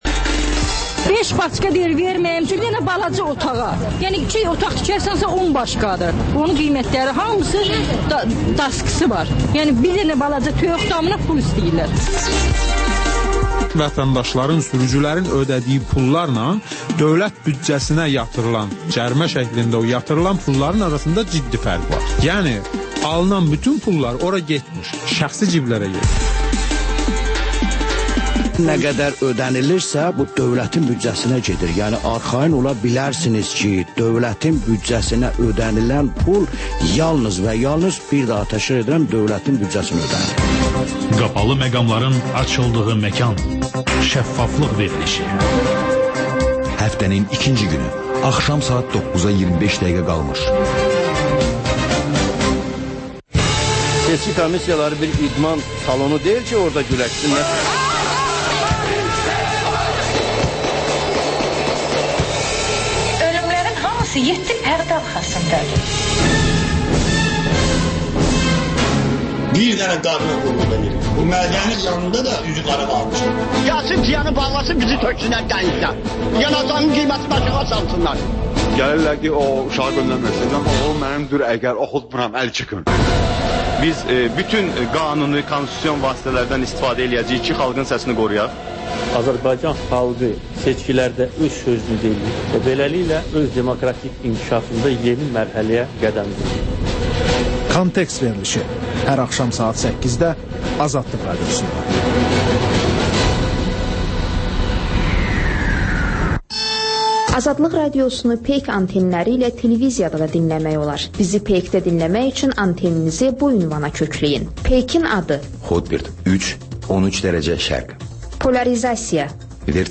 Müxbirlərimizin həftə ərzində hazırladıqları ən yaxşı reportajlardan ibarət paket